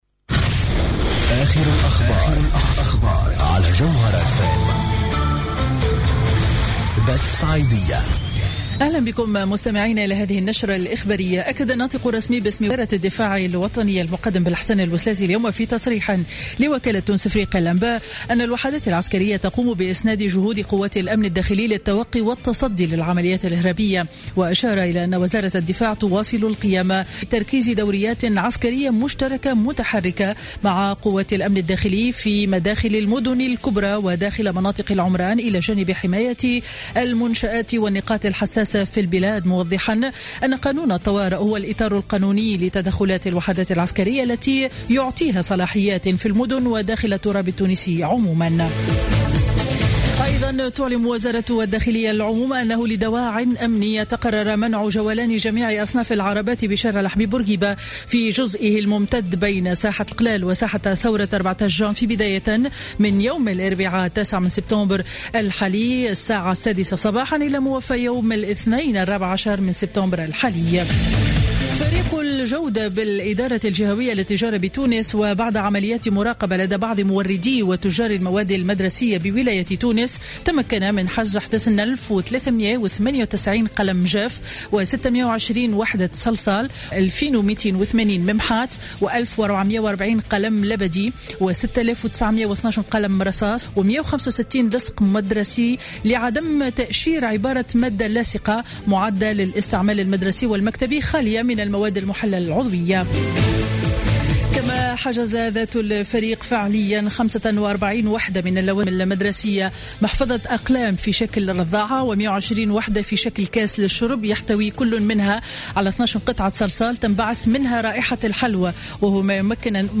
نشرة أخبار منتصف النهار ليوم الثلاثاء 8 سبتمبر 2015